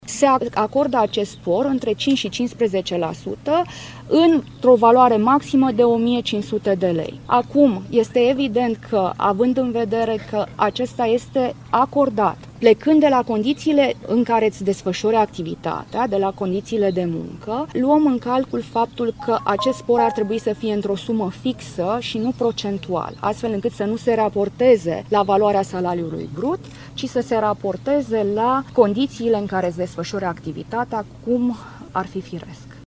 Ministra Muncii, Simona Bucura Oprescu: Luăm în calcul că acest spor ar trebui să fie într-o sumă fixă, și nu procentual